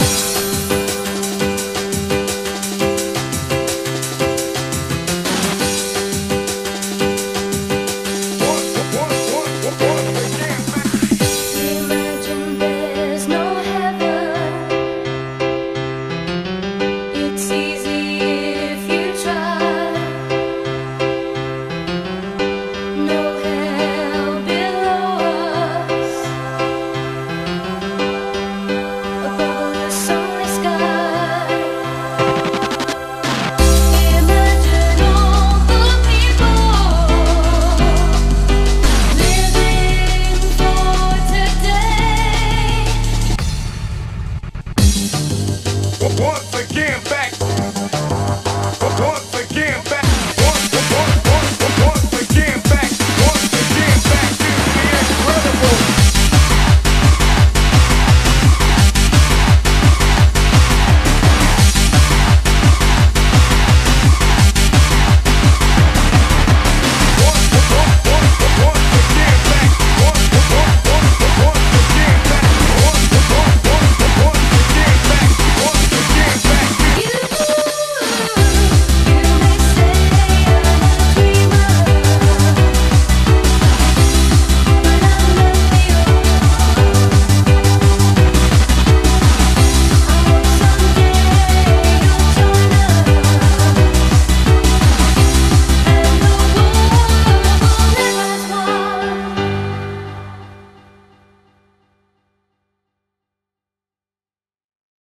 BPM86-171
Audio QualityPerfect (Low Quality)